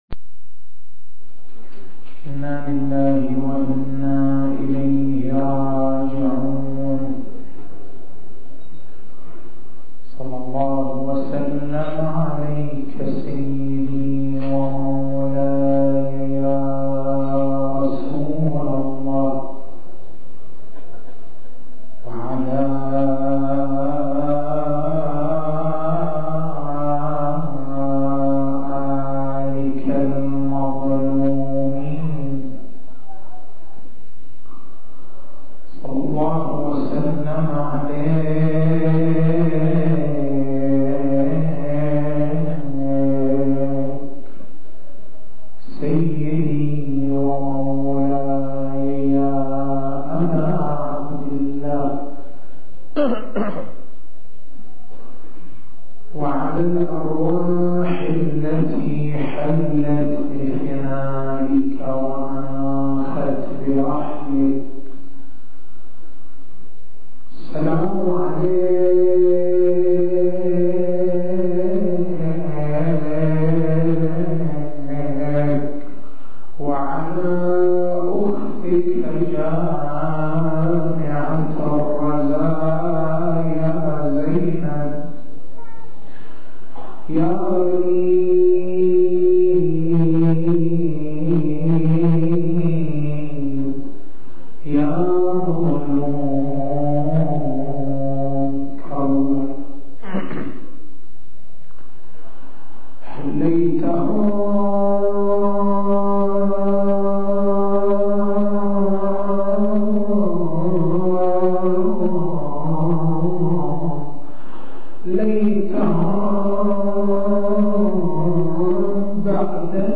تاريخ المحاضرة: 11/01/1426 نقاط البحث: المنهج الفقهي المنهج العقائدي المنهج الأخلاقي المنهج التاريخي التسجيل الصوتي: تحميل التسجيل الصوتي: شبكة الضياء > مكتبة المحاضرات > محرم الحرام > محرم الحرام 1426